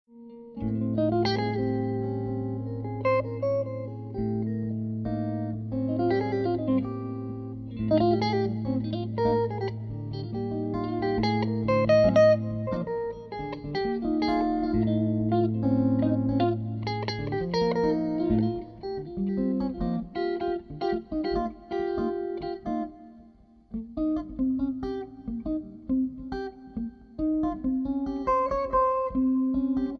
Guitar
Two Electric Guitar Entwine
Lyrical Jazz, Blues and Rock inflected Duets